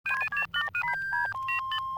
hail.wav